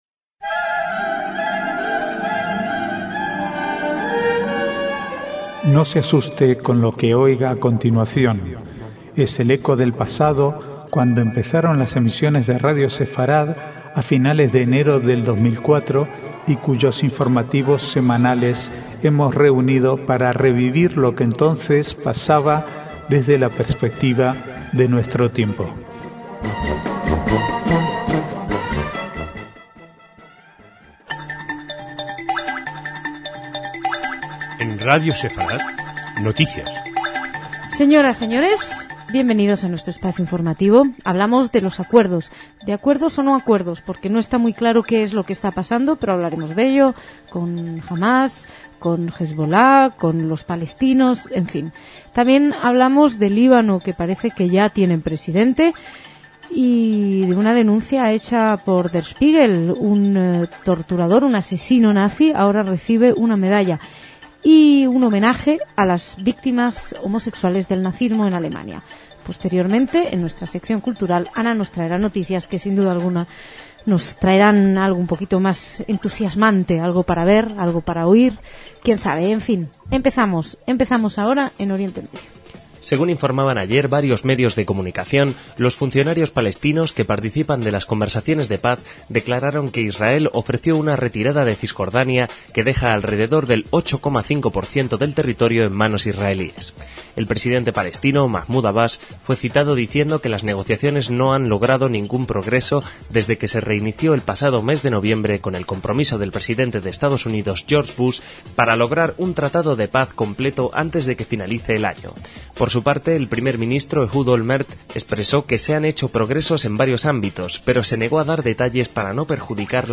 Archivo de noticias del 27 al 29/5/2008